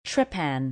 Watch out! You may see this word with another pronunciation and its meaning is then different.